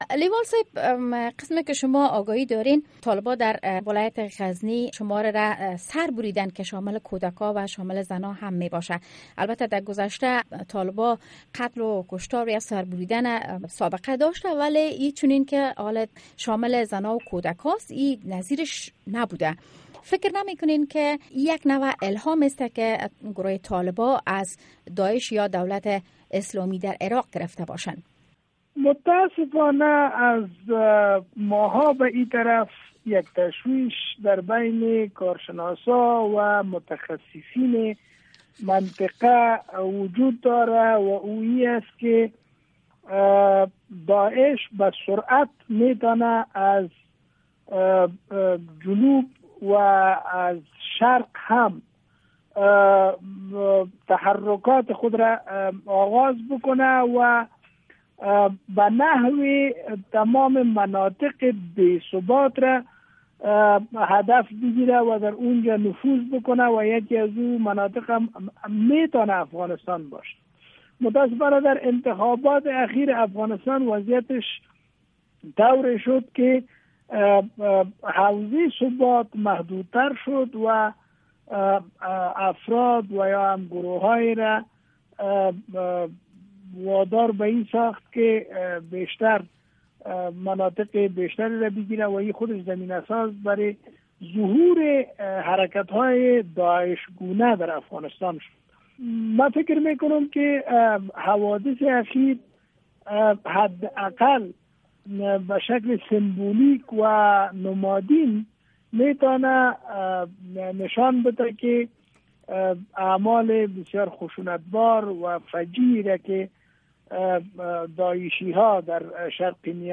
شرح بیشتر مصاحبه